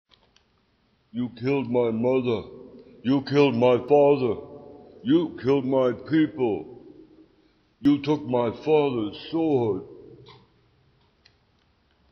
I had an old Arnold Schwarzenegger voice from the 1980s that I had never listen to.
But I have done it almost perfectly Conan because of that attempt is perfect even if I was laughing at the start.